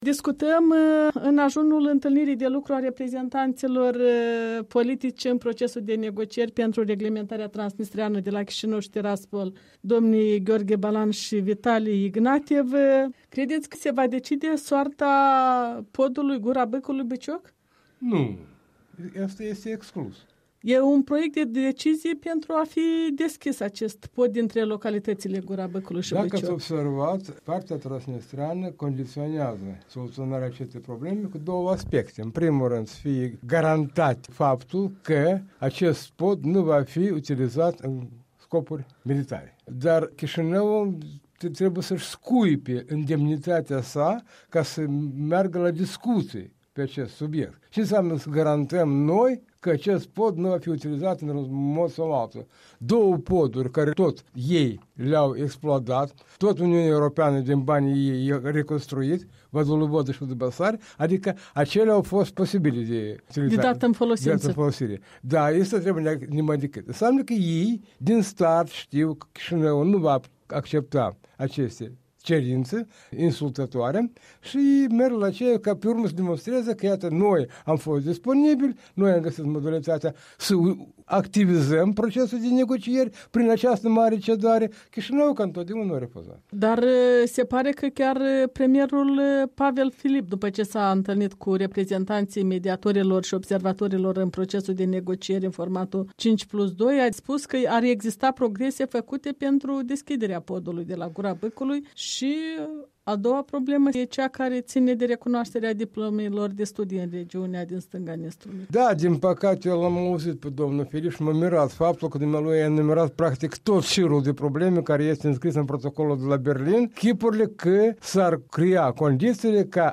Despre conflictul transnistrean și raporturile dintre Chișinău și Tiraspol un interviu cu Ion Leahu